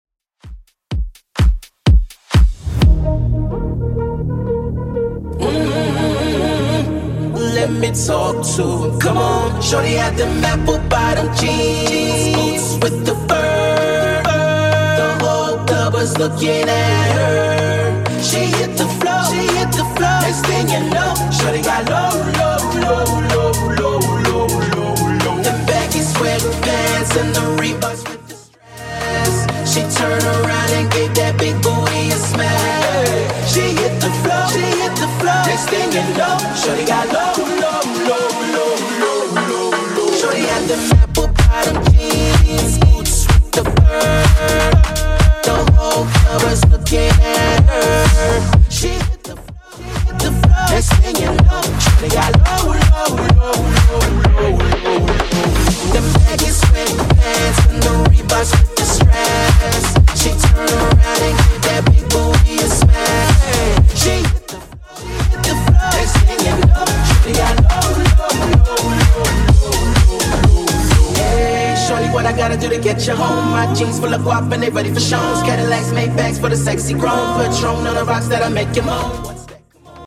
Genre: 80's
BPM: 116